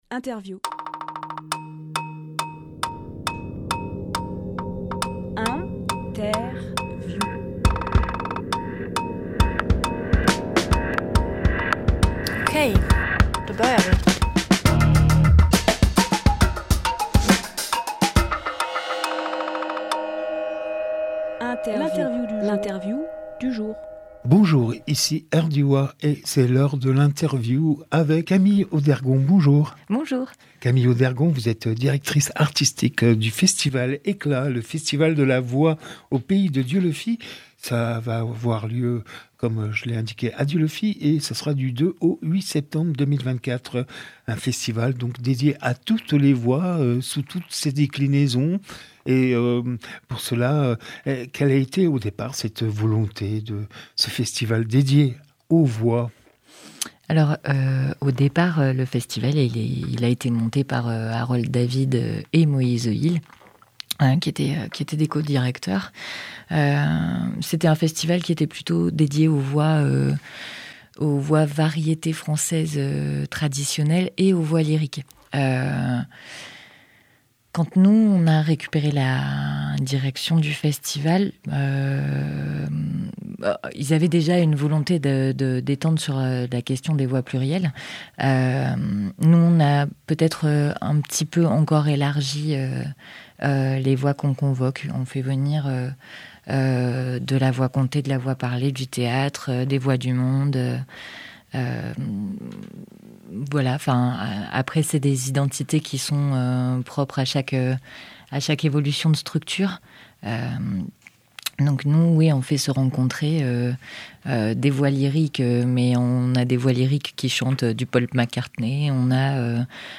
Emission - Interview Eclats Festival de la Voix au Pays de Dieulefit Publié le 24 août 2024 Partager sur…
Lieu : Studio Rdwa